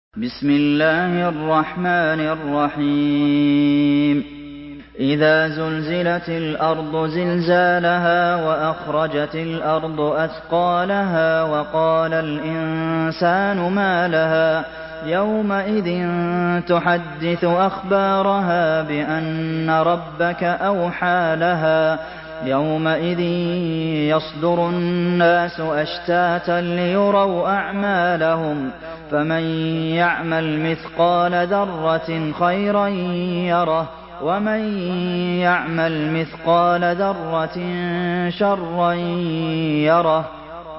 Surah Az-Zalzalah MP3 by Abdulmohsen Al Qasim in Hafs An Asim narration.
Murattal Hafs An Asim